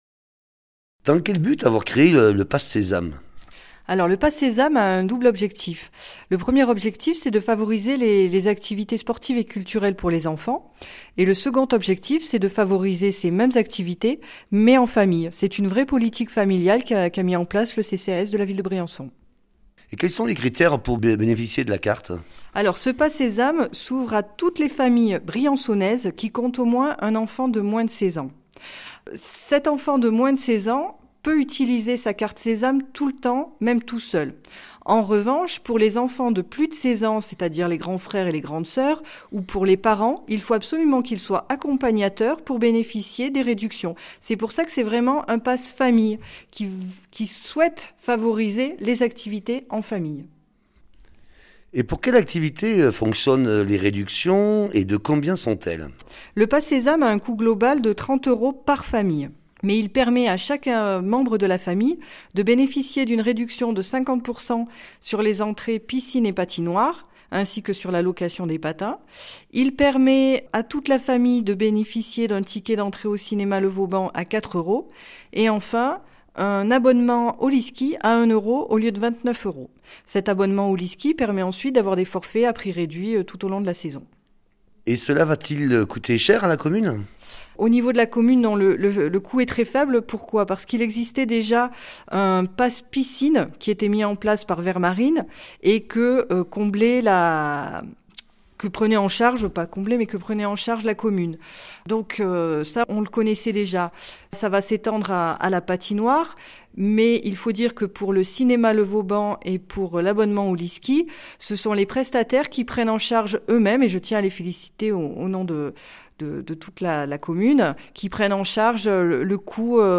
Catherine Guigli, adjointe au Maire en charge de la politique des solidarités détaille cette offre tarifaire